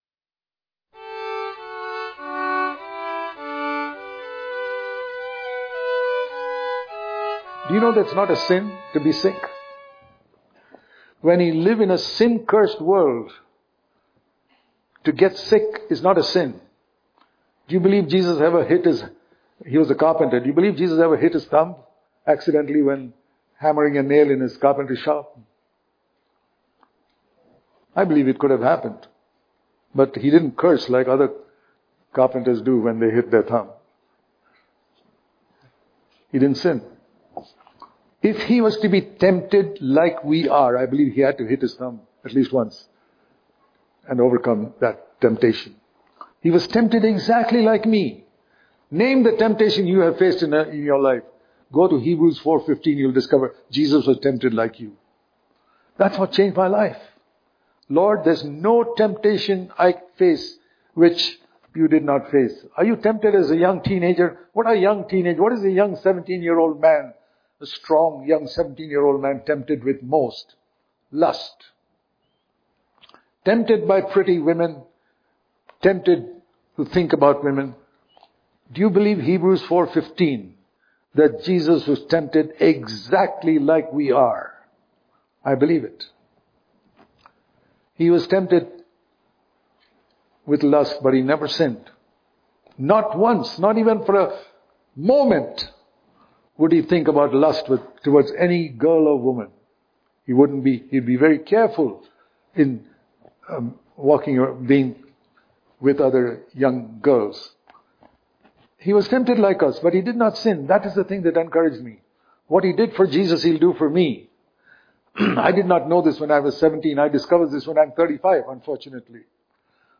December 4 | Daily Devotion | Jesus Was Heard Because Of His Godly Fear Daily Devotion